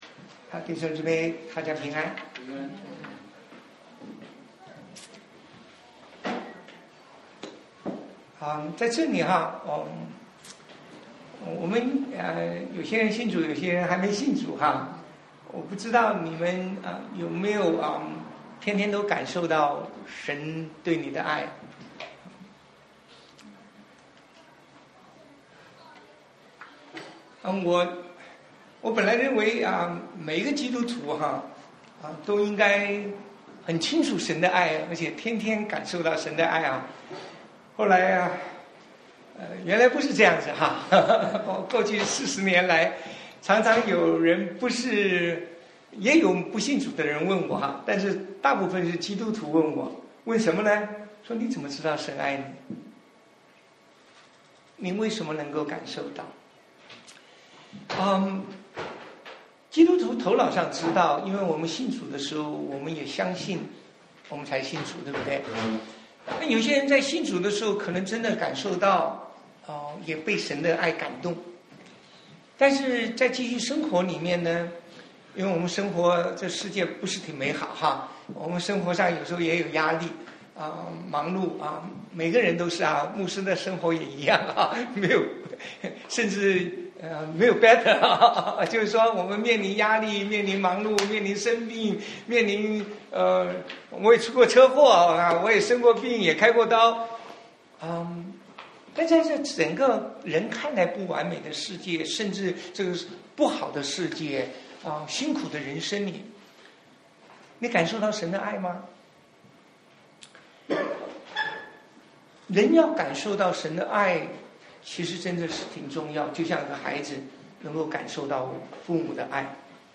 Play Audio 聽講道錄音 蒙愛與行善（一）： 勝過罪過 羅馬書 5:1- 8 引言 : 你如何知道神愛你？ 本論 : (一) 拯救 (二) 管教 (三) 赦免 (四) 我們的回應 結論 : 讓我們不僅接受神的愛而且效法神以愛勝過罪惡， 讓我們靠主活出愛的生命來彰顯神的恩典與榮耀。